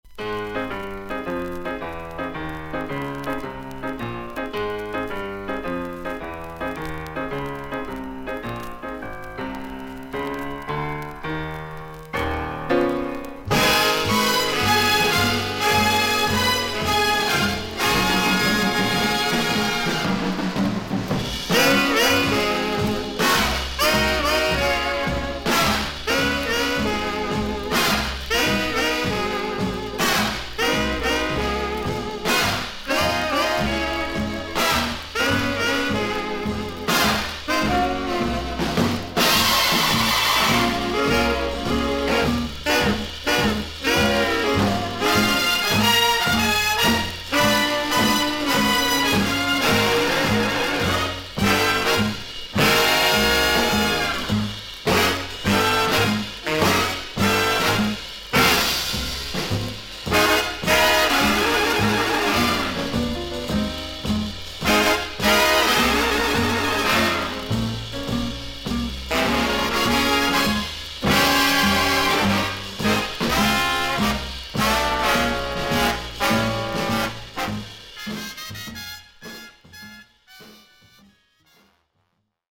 少々軽いパチノイズの箇所あり。少々サーフィス・ノイズあり。クリアな音です。
ジャズ・ピアニスト/男性ジャズ・シンガー。